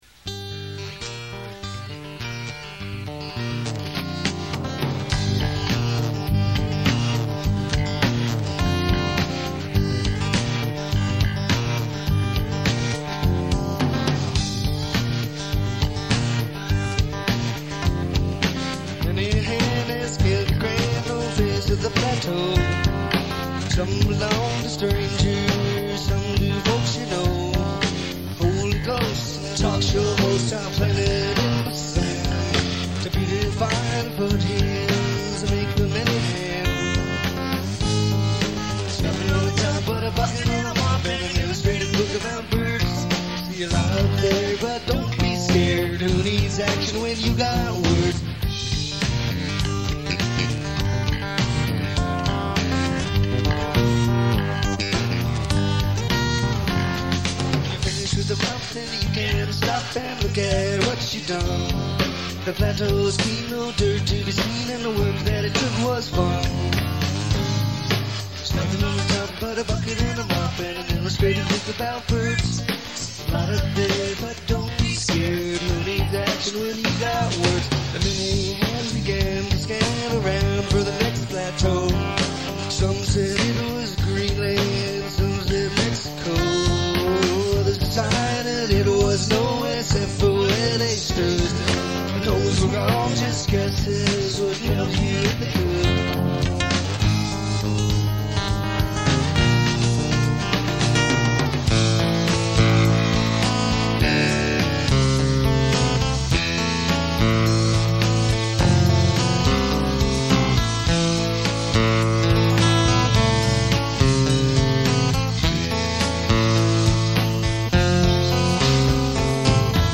Slims, San Francisco, CA